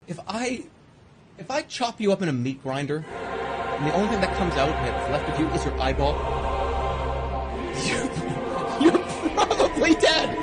Meat Grinder Téléchargement d'Effet Sonore
Meat Grinder Bouton sonore